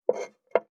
548魚切る,肉切りナイフ,まな板の上,
効果音厨房/台所/レストラン/kitchen食器食材